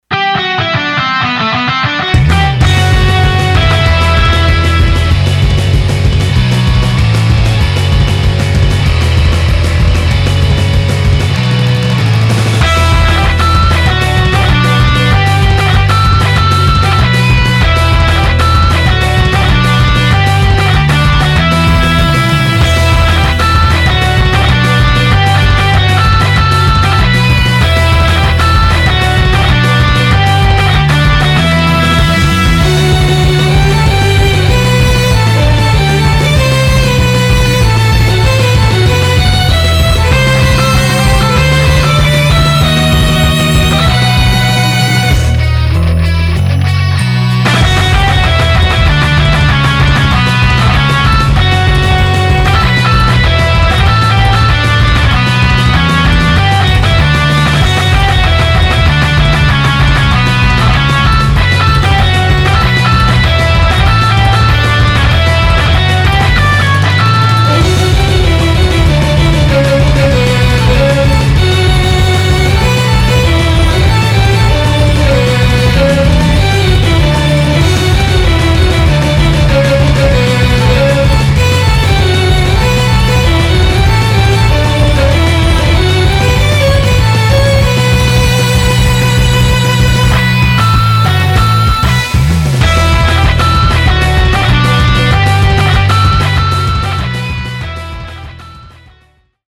フリーBGM フィールド・ダンジョン フィールド探索・疾走感
フェードアウト版のmp3を、こちらのページにて無料で配布しています。